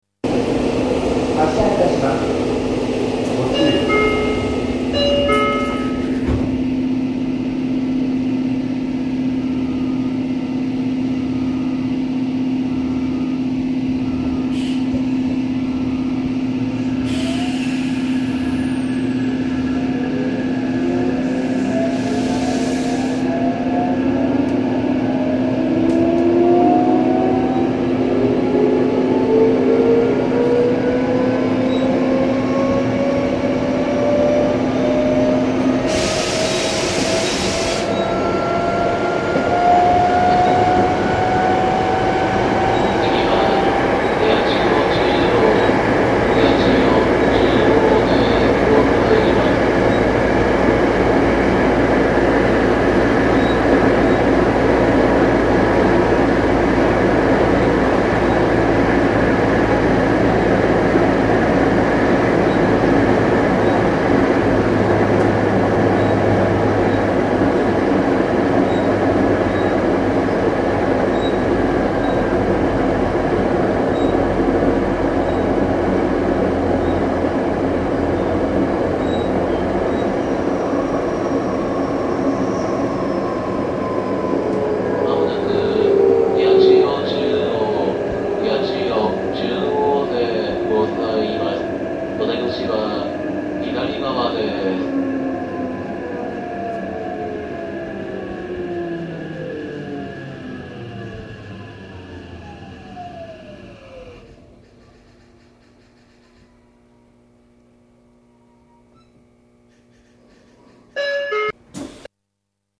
新０５系走行音 落合→高田馬場 313Kb RealAudio形式
音の大きさが若干低めですが、独特の音が聞こえてきます。